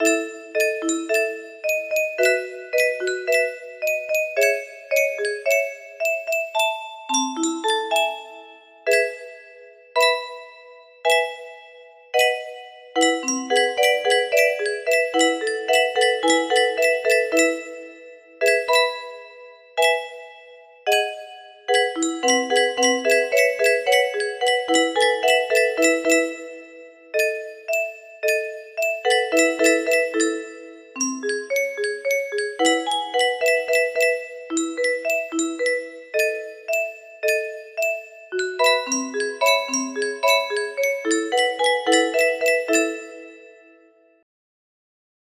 piano solo ver